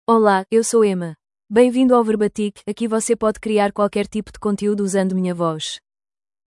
FemalePortuguese (Portugal)
EmmaFemale Portuguese AI voice
Emma is a female AI voice for Portuguese (Portugal).
Voice sample
Emma delivers clear pronunciation with authentic Portugal Portuguese intonation, making your content sound professionally produced.